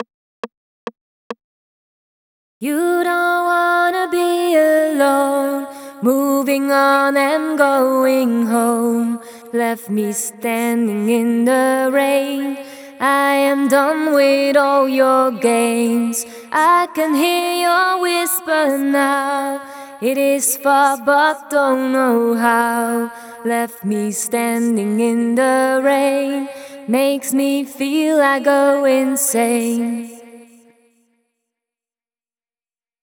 16 - Try (138 BPM)
Verse FX.wav